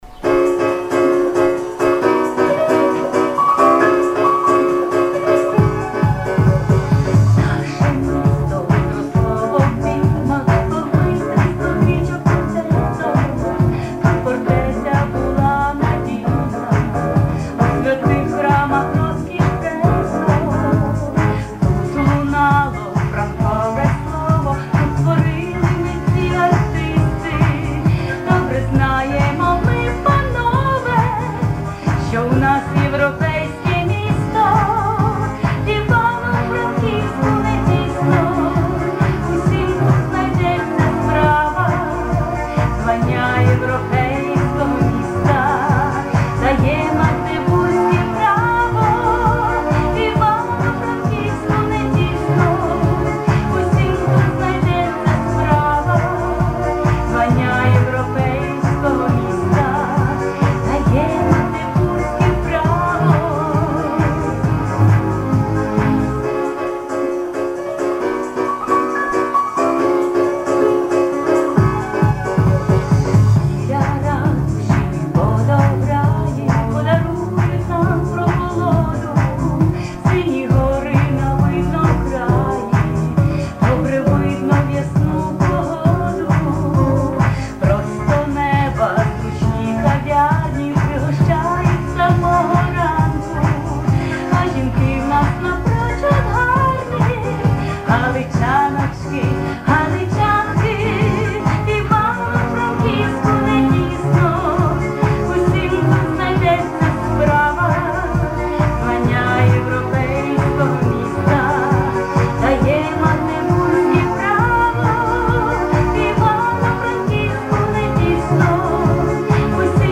А у кінці, як виявилося, причепилося трохи "Дощових мадонн" (варіант з моєю музикою, а не Миколи Шевченка; і у моєму власному виконанні, за перепрошенням :-)